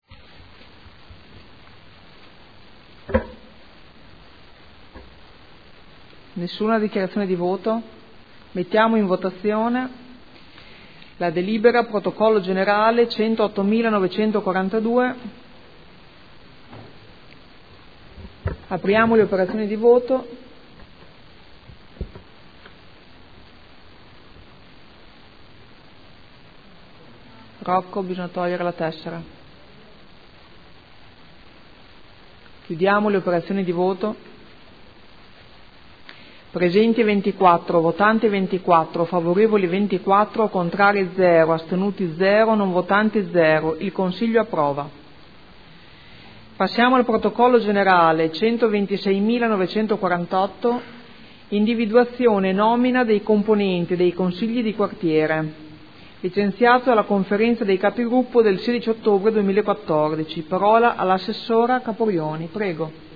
Presidente — Sito Audio Consiglio Comunale
Seduta del 16 ottobre.